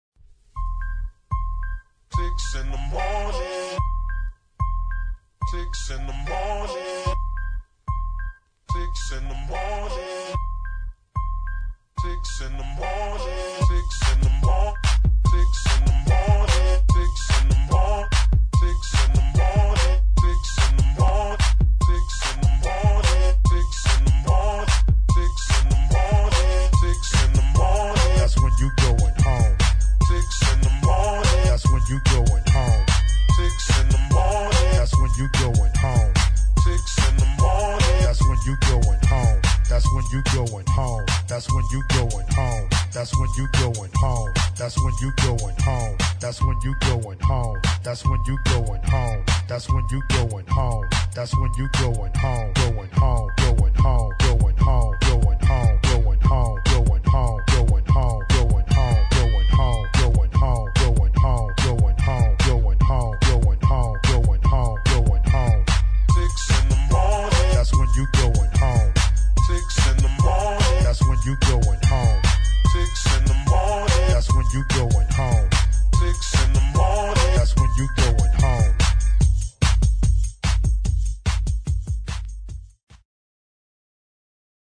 [ GHETTO | TECHNO | ELECTRONIC ]